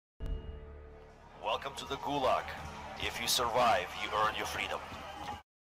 PLAY warzone soundboard Welcome to the gulag.